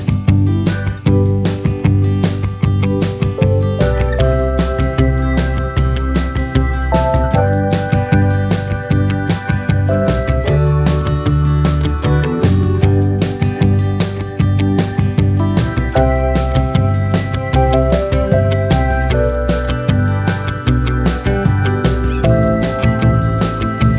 no Backing Vocals Soft Rock 3:08 Buy £1.50